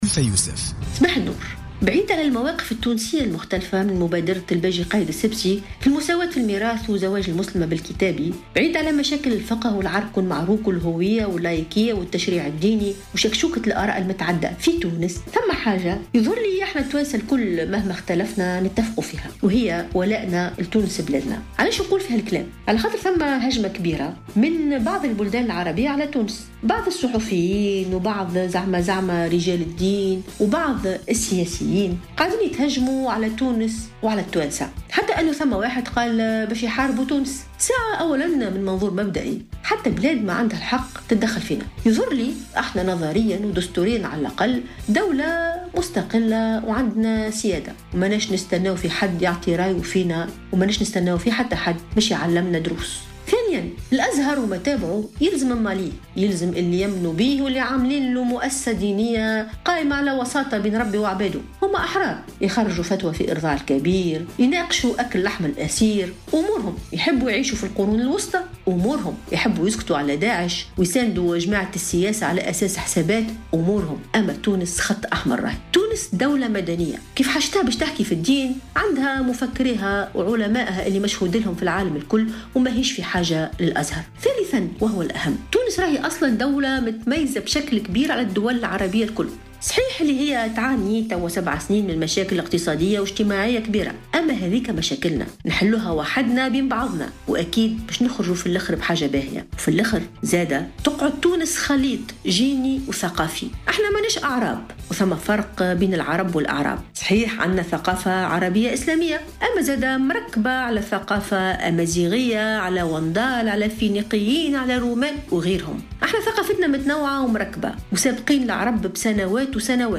توجّهت اليوم الخميس الكاتبة ألفة يوسف، المتخصصة في الحضارة الإسلامية، برسالة إلى مؤسسة "الأزهر الشريف" في افتتاحيتها الصّوتية على "الجوهرة أف أم"، قالت فيها إن تونس ليست بحاجة لـ "الأزهر".